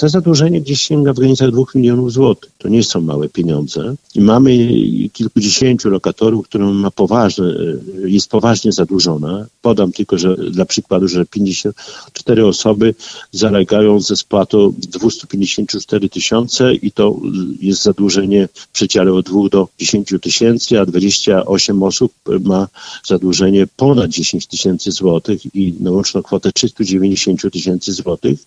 O szczegółach mówi Czesław Renkiewicz, prezydent Suwałk.